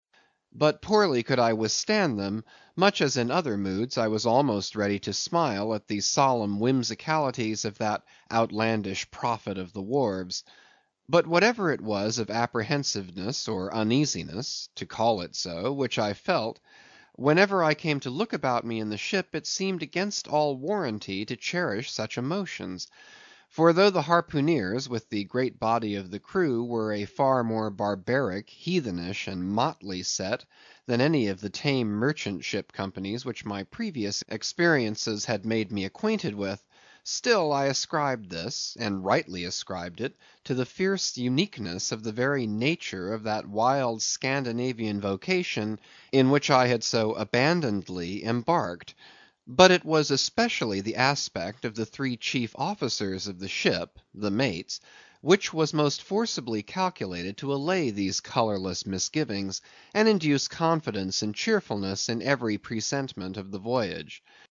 英语听书《白鲸记》第160期 听力文件下载—在线英语听力室